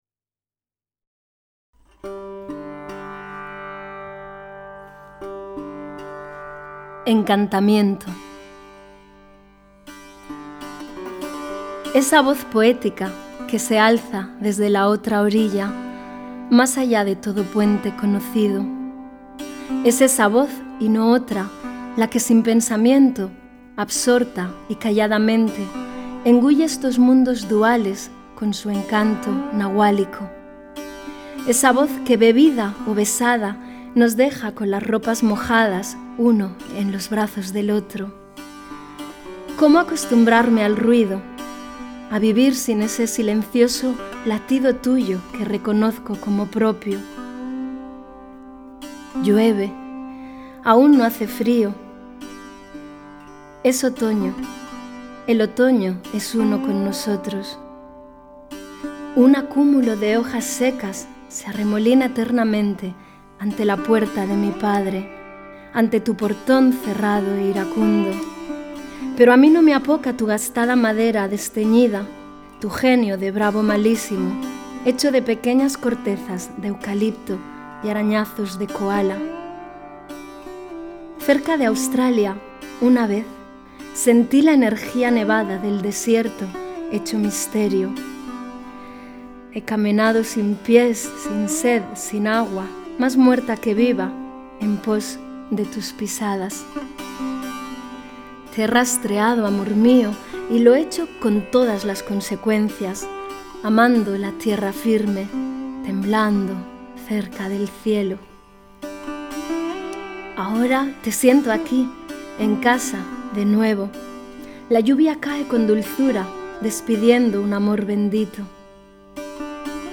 encantamiento-CON-sitar.mp3